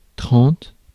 Ääntäminen
Ääntäminen France: IPA: /tʁɑ̃t/ Haettu sana löytyi näillä lähdekielillä: ranska Käännöksiä ei löytynyt valitulle kohdekielelle.